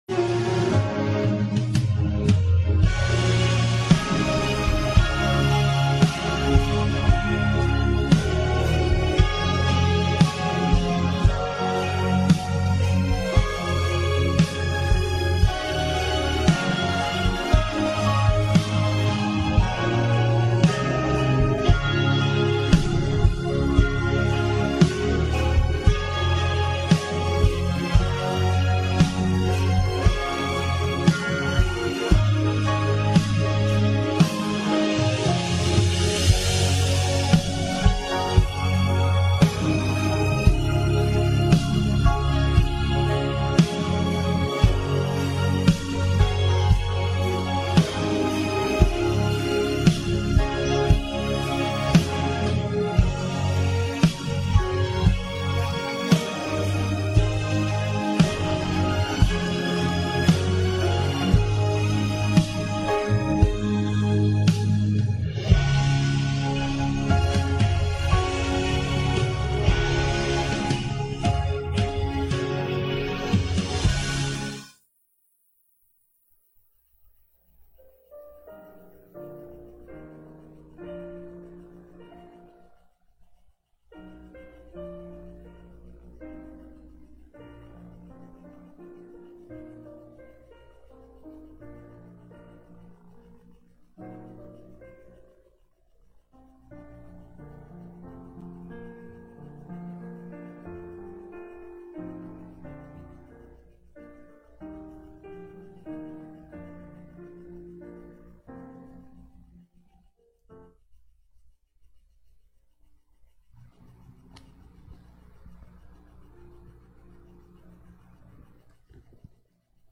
Psalm 129:1-8 Service: Sunday Sermon Outline